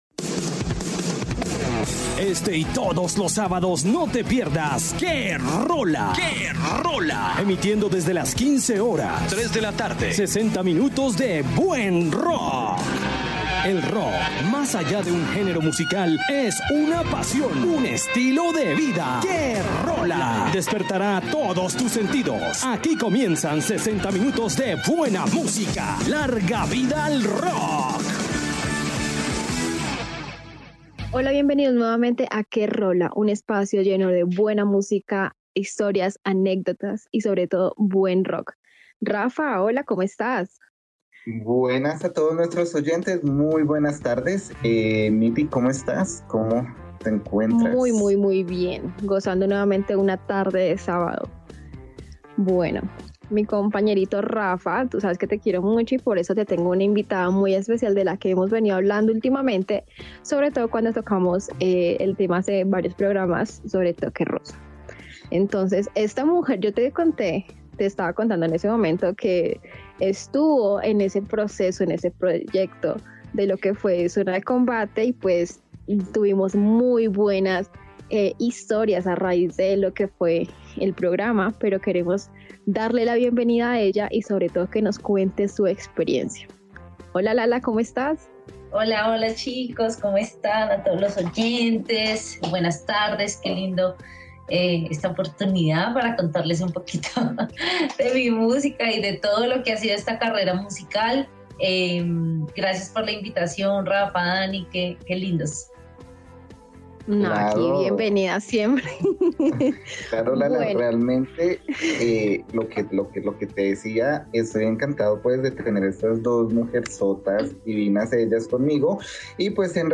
Acompañanos a escuchar esta increible entrevista. QRola